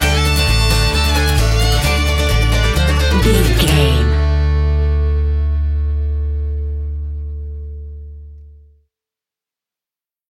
Ionian/Major
D
acoustic guitar
banjo
bass guitar
violin
Pop Country
country rock
bluegrass
uplifting
driving
high energy